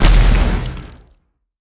sk02_explosion.wav